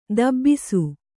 ♪ dabbisu